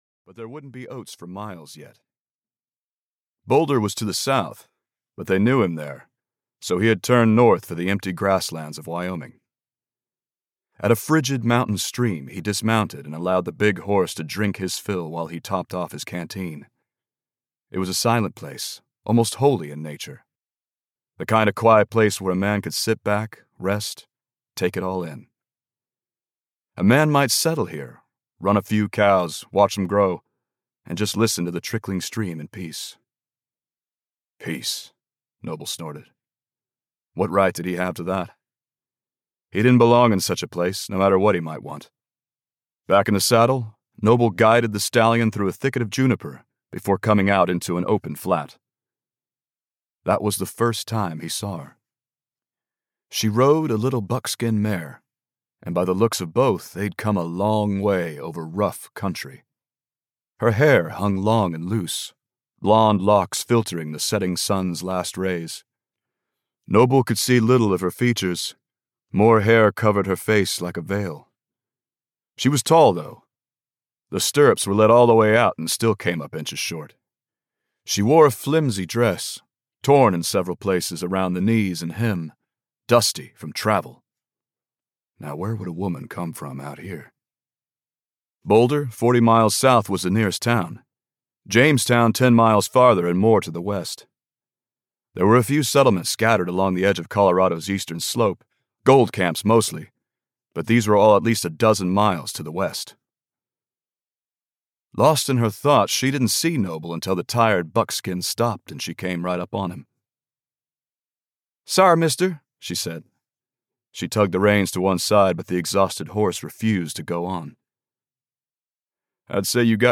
Gun Thunder (EN) audiokniha
Ukázka z knihy